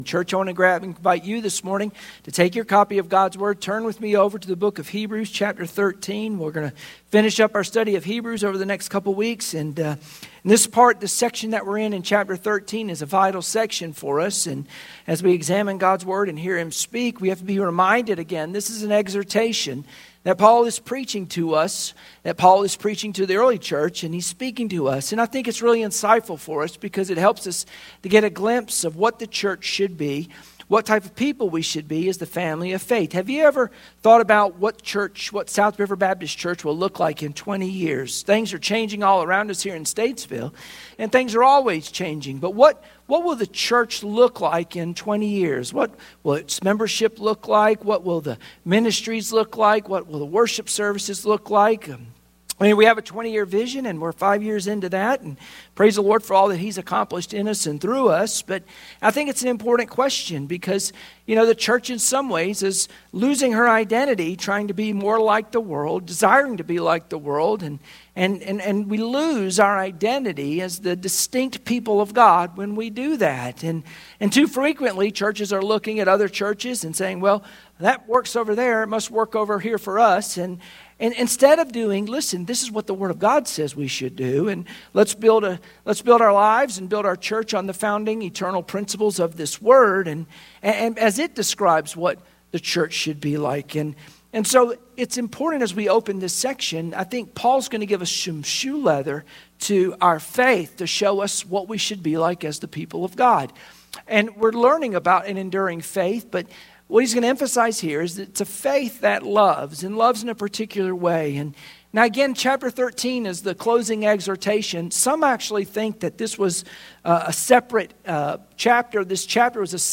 Sunday Morning Worship Passage: Hebrews 13:1-6 Service Type: Sunday Morning Worship Share this